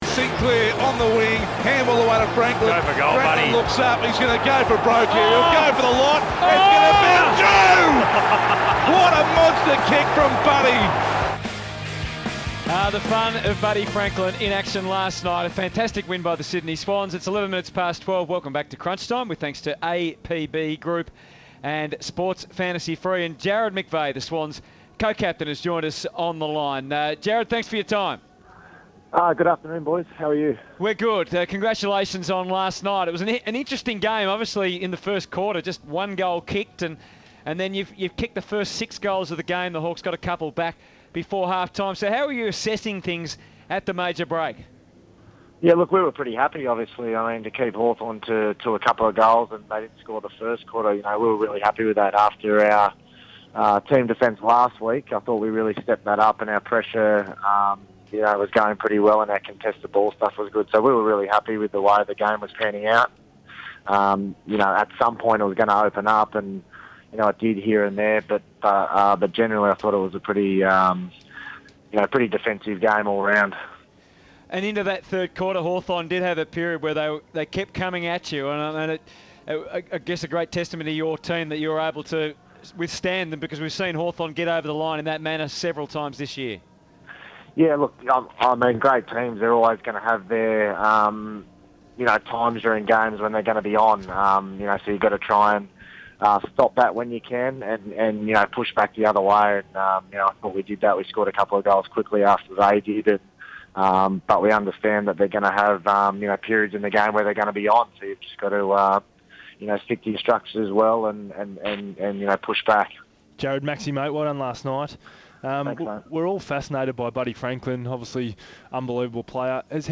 Jarrad McVeigh speaks to the team on Crunchtime on SEN Radio.